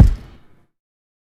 HFMKick4.wav